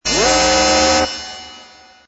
2_second_loop_klaxon.wav